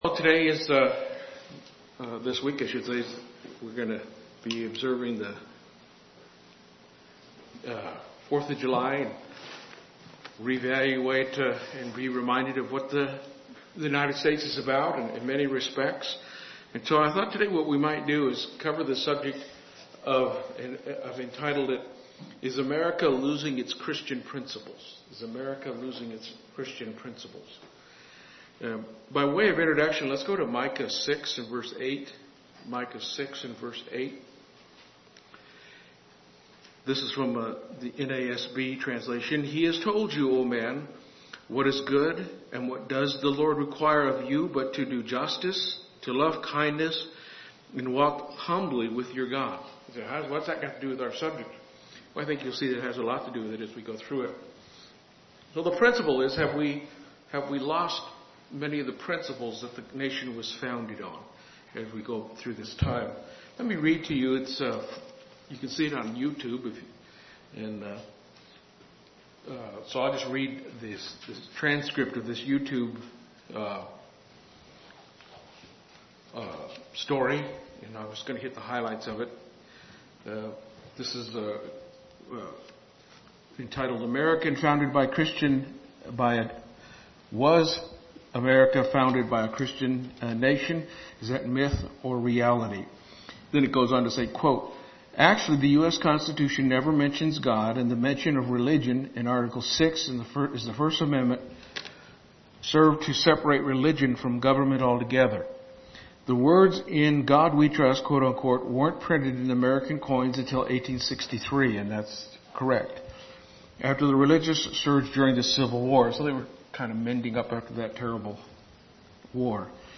The United States was founded on Christian principles. Listen to this sermon to learn what those principles are.